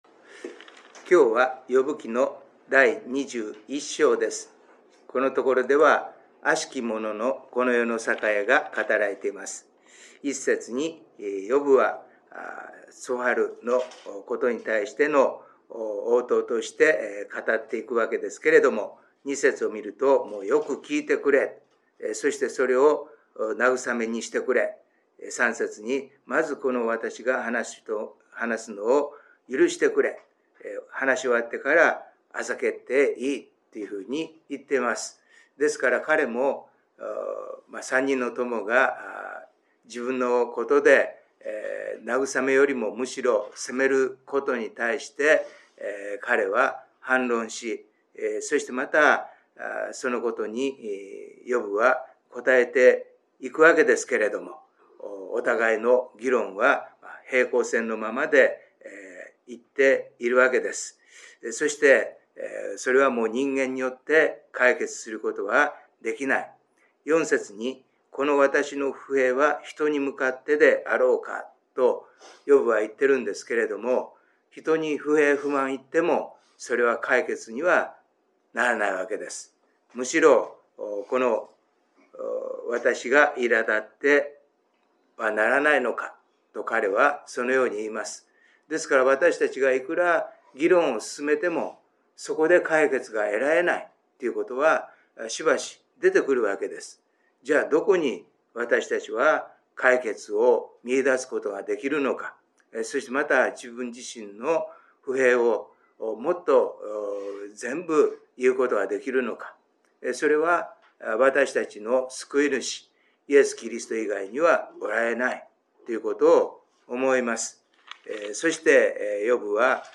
6月のデボーションメッセージ